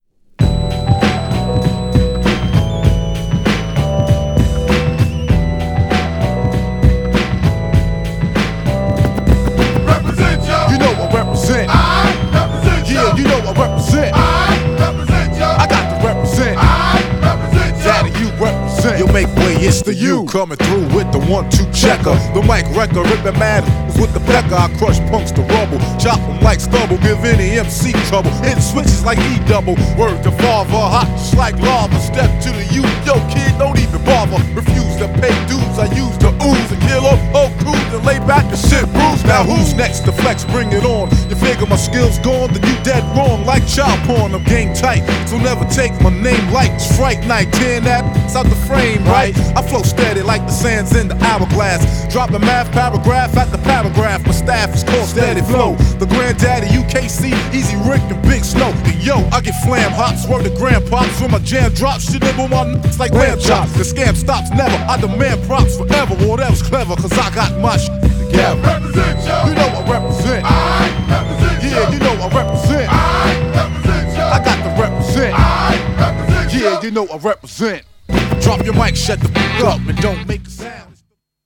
GENRE Hip Hop
BPM 101〜105BPM